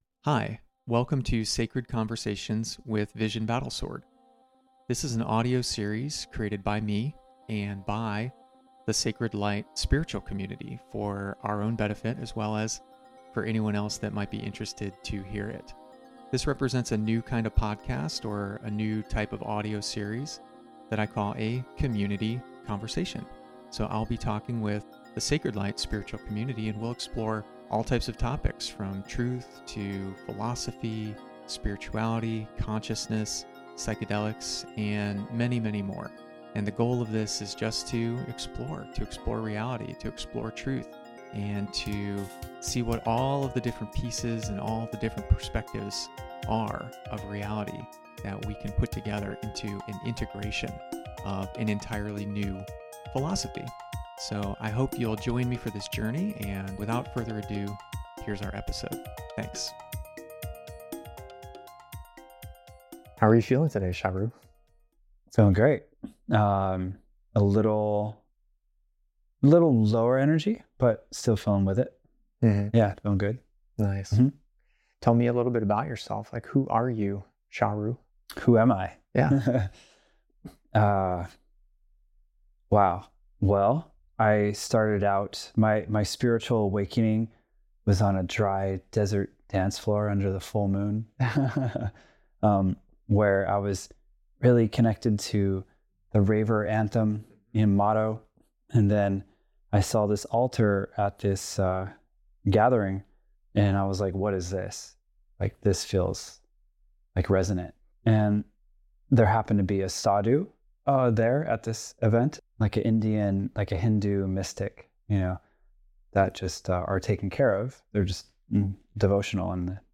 conversation28-gratitude.mp3